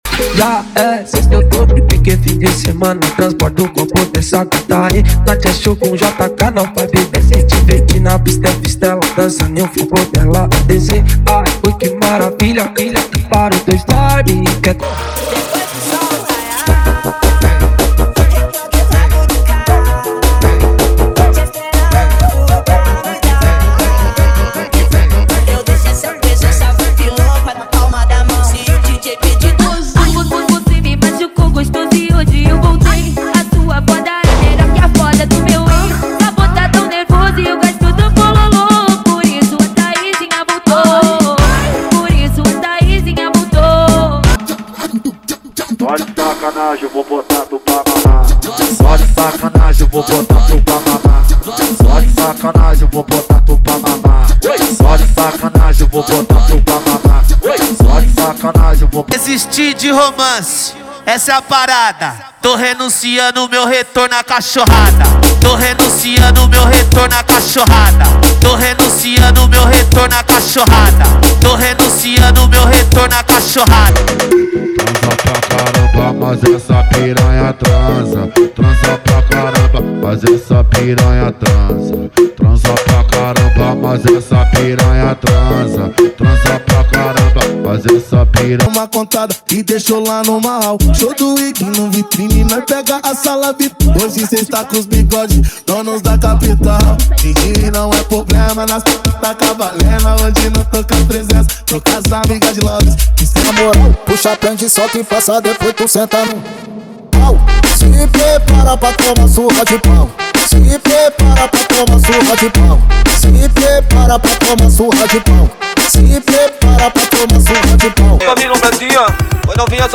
Os Melhores Brega Funk do momento estão aqui!!!
• Sem Vinhetas
• Em Alta Qualidade